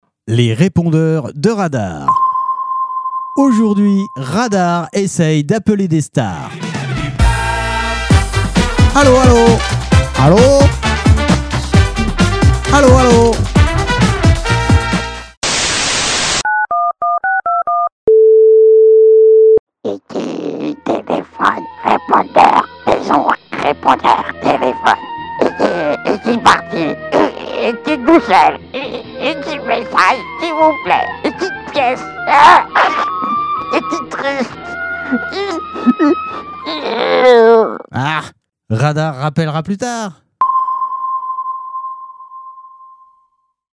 Les répondeurs de Radar parodies répondeurs stars radar